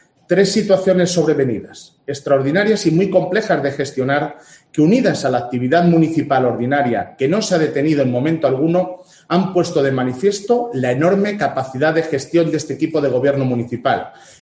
Alcalde de Ávila. Pleno debate estado ciudad